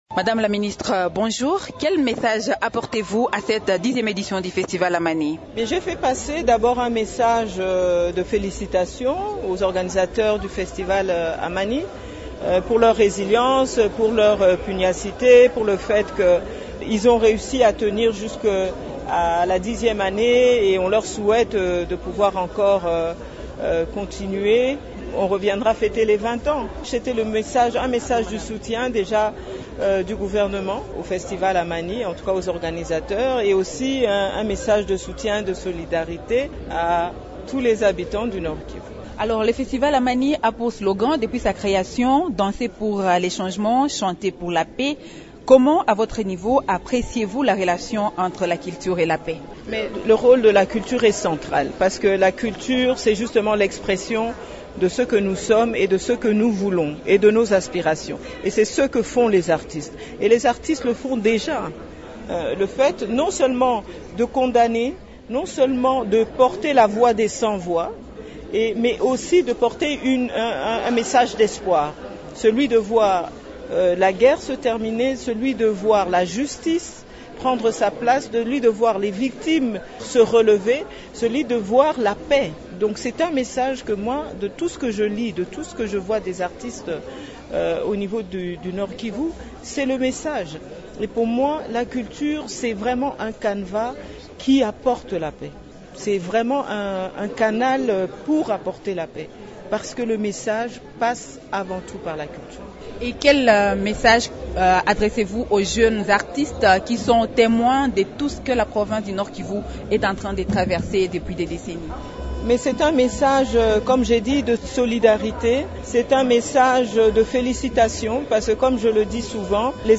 A l’issue de la clôture du festival Amani, le même dimanche dans la soirée, la ministre a exprimé son appréciation face à ce grand rendez-vous culturel du Nord-Kivu. Elle s’entretient à ce sujet